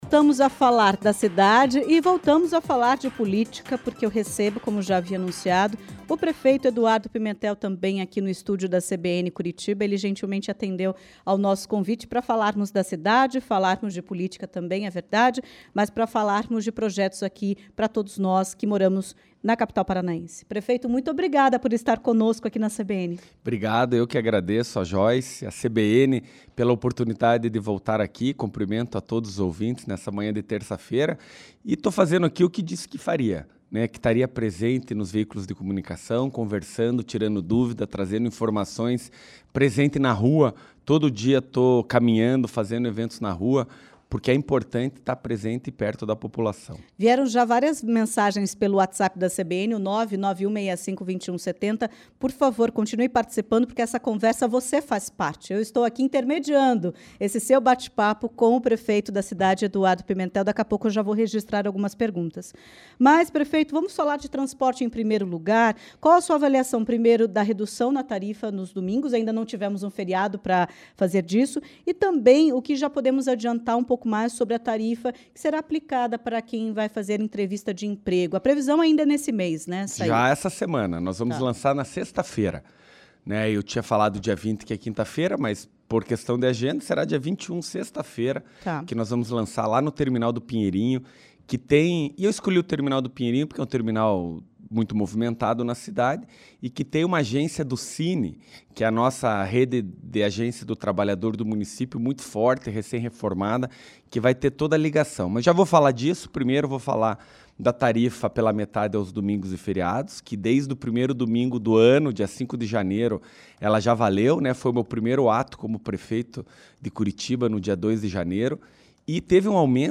A declaração foi feita em entrevista à CBN Curitiba na manhã desta terça-feira (18).